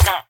mob / villager / hit1.ogg
hit1.ogg